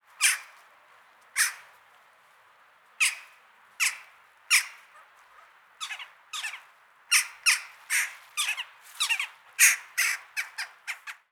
Dohle Ruf
Der typische Dohle Ruf – Laut, kurz und metallisch
Das auffälligste Merkmal der Dohle ist ihr krächzender Ruf, der oft wie ein „kjack“ oder „kjäh“ klingt. Dieser Laut ist kurz, scharf und metallisch, wirkt aber gleichzeitig variabel.
Dohle-Ruf-Voegel-in-Europa.wav